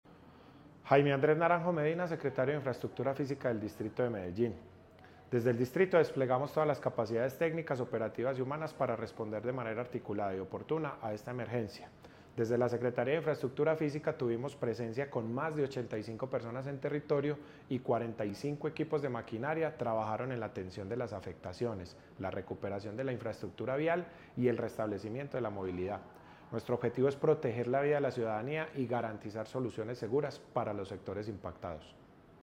Declaraciones del secretario de Infraestructura Física, Jaime Andrés Naranjo Medina
Declaraciones-del-secretario-de-Infraestructura-Fisica-Jaime-Andres-Naranjo-Medina-2.mp3